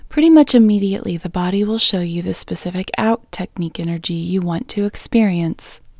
LOCATE Short OUT English Female 10